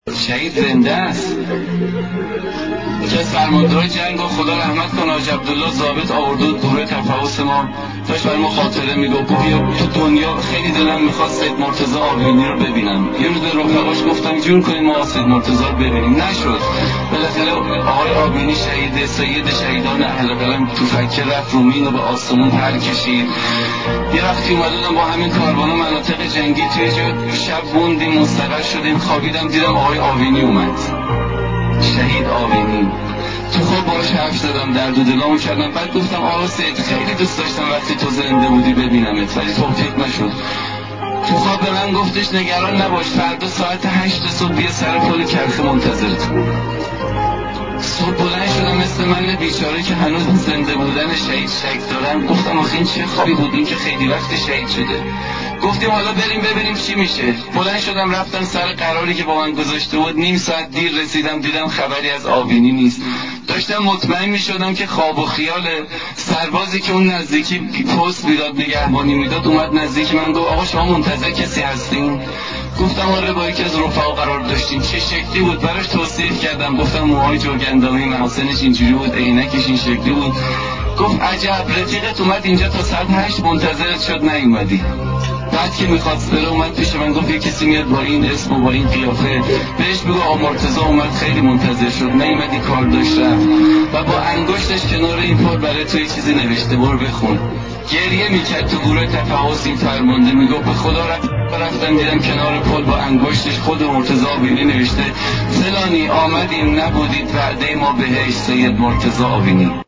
روایتگری
ravayatgari191.mp3